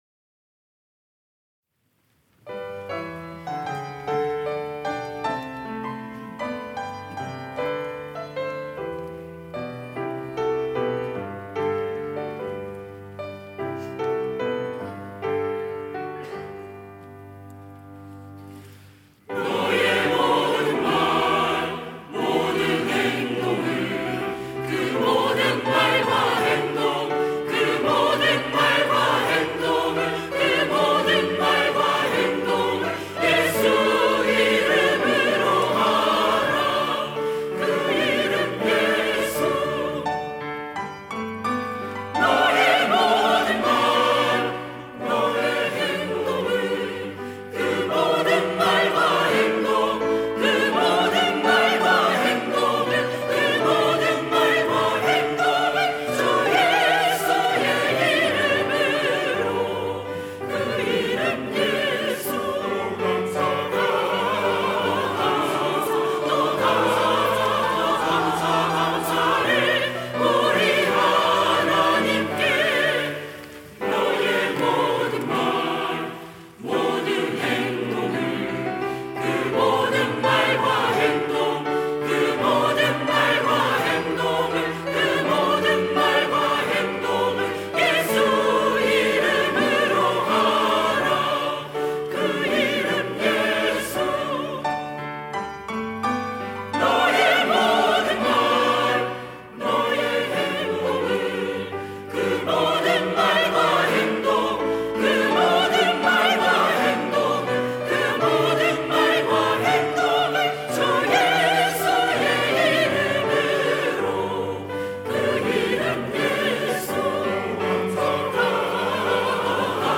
시온(주일1부) - 너의 모든 말과 행동
찬양대